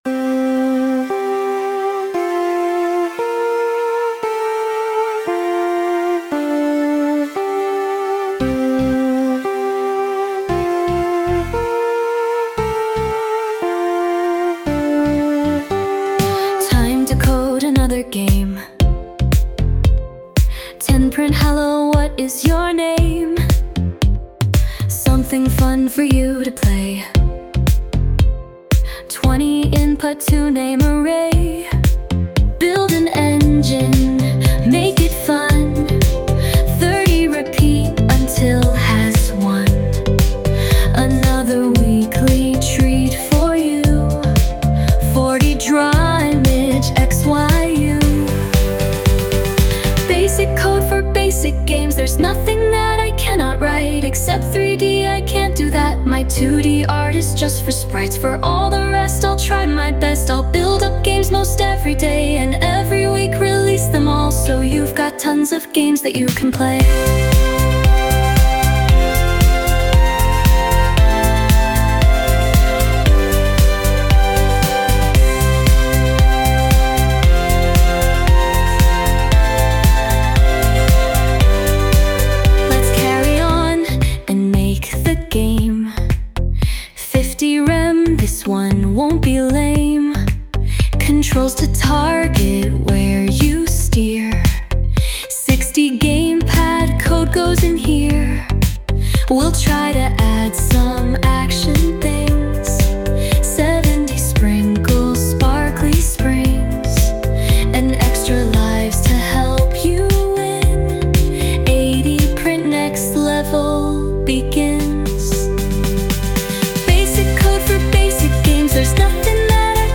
Sound Imported : Youthful Q2
Sung by Suno